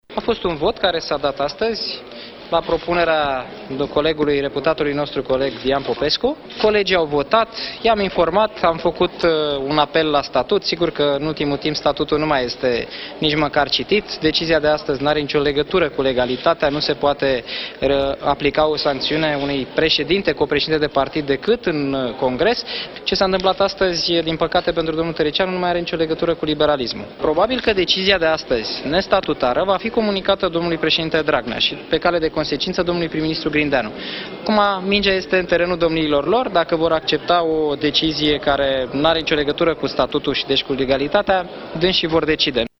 Daniel Constantin a declarat la ieșirea de la ședință că decizia Biroului Politic Central al ALDE este nestatutară, iar în acest moment ”mingea” este în terenul liderului PSD Liviu Dragnea şi a premierului Sorin Grindeanu, care trebuie să decidă dacă acceptă o decizie nestatutară: